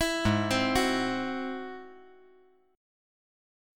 G#+7 Chord
Listen to G#+7 strummed